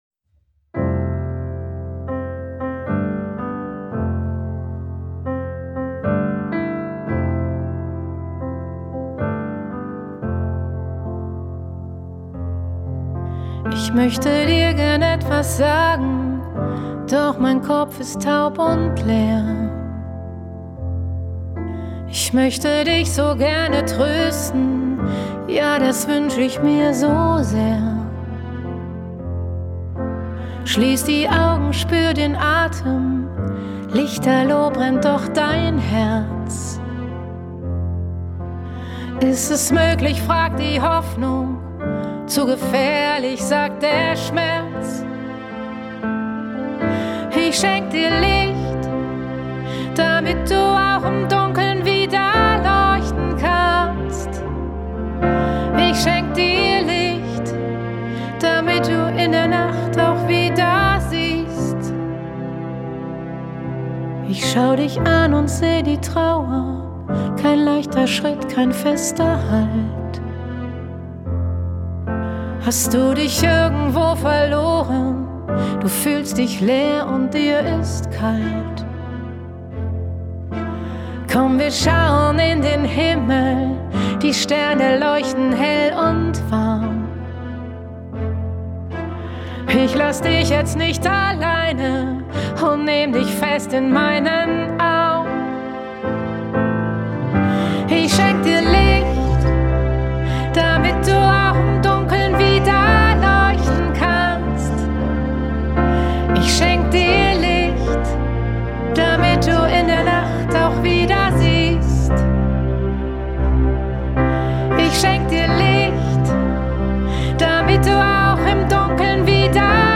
„Licht“ – Eigenproduktion
Sie singt mit so viel Gefühl und und Leidenschaft, es ist wirklich unglaublich.